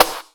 • Sizzle Snare Single Hit B Key 43.wav
Royality free snare drum sample tuned to the B note. Loudest frequency: 3139Hz
sizzle-snare-single-hit-b-key-43-7ZY.wav